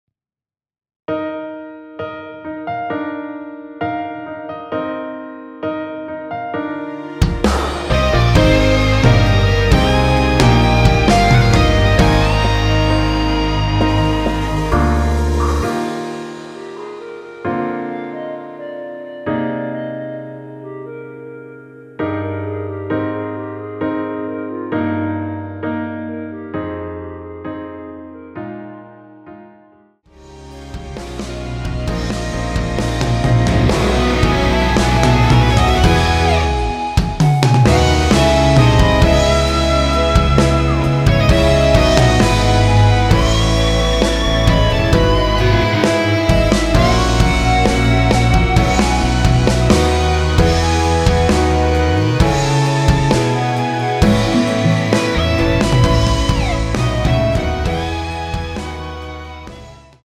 원키에서(+2)올린 멜로디 포함된 MR입니다.(미리듣기 확인)
Eb
앞부분30초, 뒷부분30초씩 편집해서 올려 드리고 있습니다.
중간에 음이 끈어지고 다시 나오는 이유는